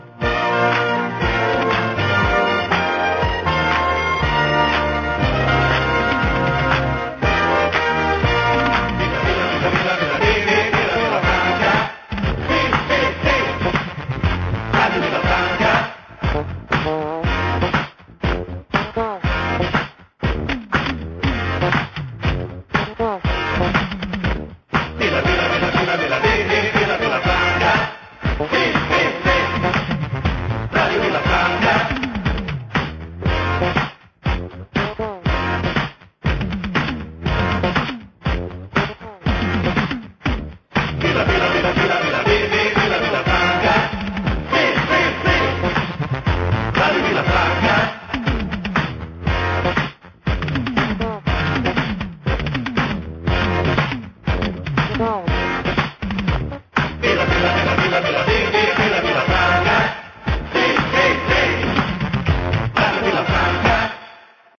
Sintonia cantada de l'emissora